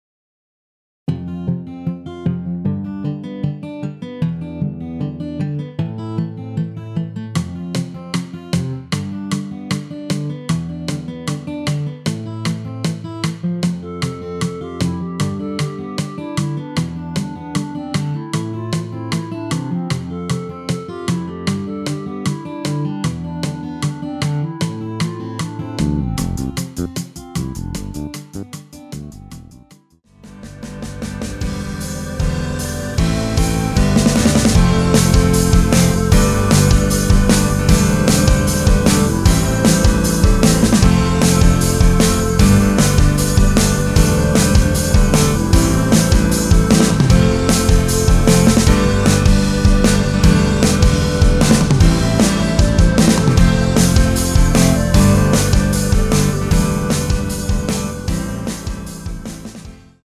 원키에서(-2)내린 멜로디 포함된 MR입니다.
Am
앞부분30초, 뒷부분30초씩 편집해서 올려 드리고 있습니다.
중간에 음이 끈어지고 다시 나오는 이유는